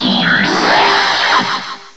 cry_not_pheromosa.aif